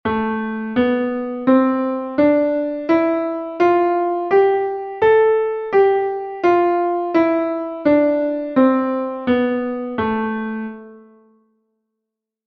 Lam (audio/mpeg)